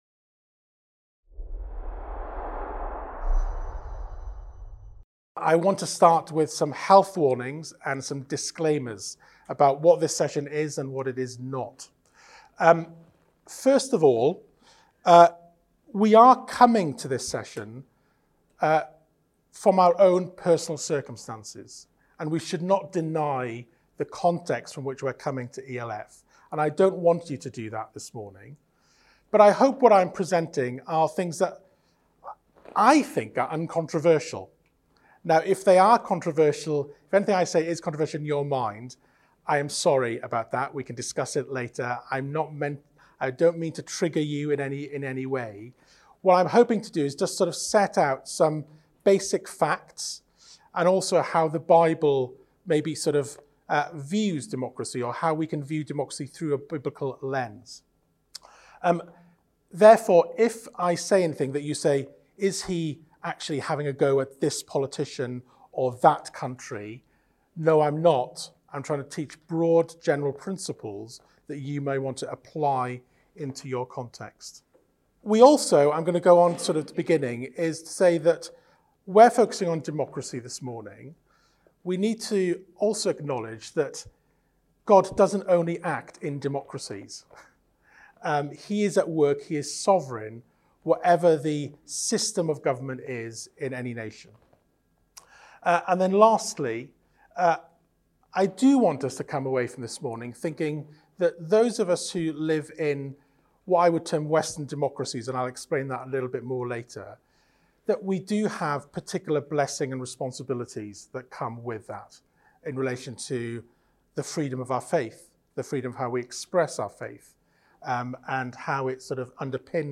Event: ELF Politics and Society Network